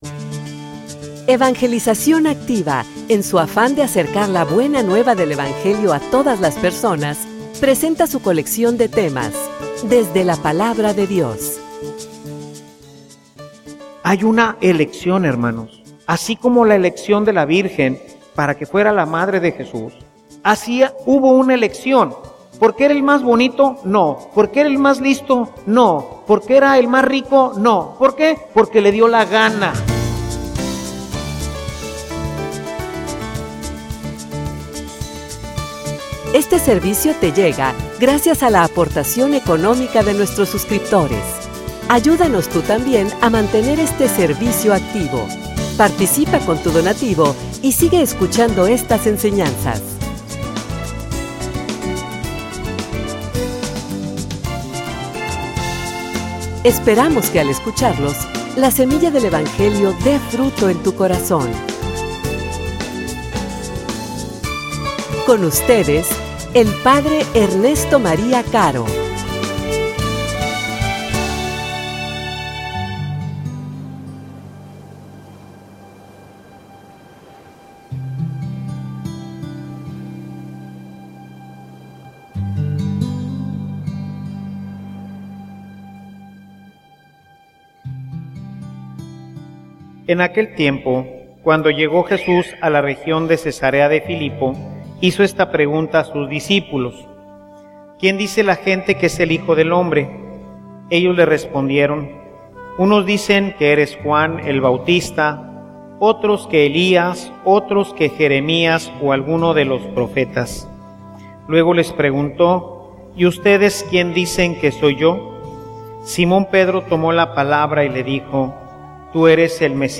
homilia_La_roca_de_la_iglesia.mp3